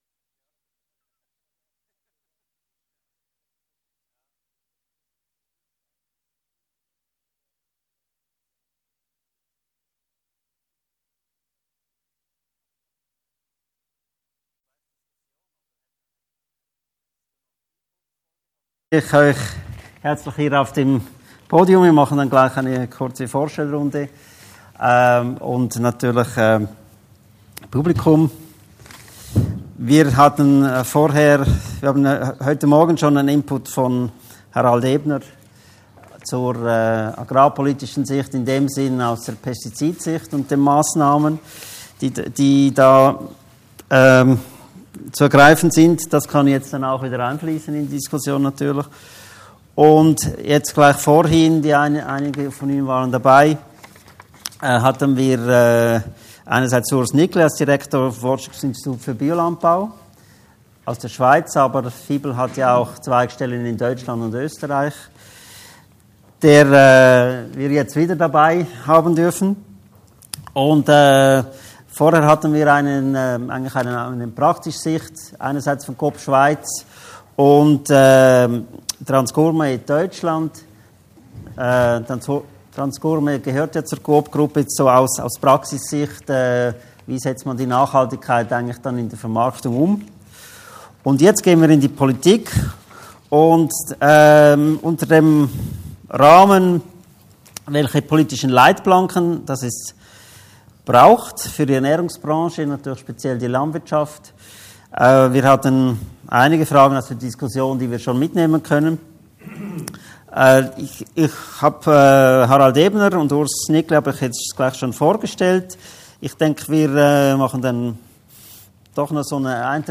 Mitschnitt Diskussion Bio-Kompetenzzentrum am 9.10.2017 [52 MB]
diskussion_politische-leitplanken-fuer-die-ernaehrungsbranche_01h_09m.mp3